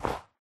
snow3.ogg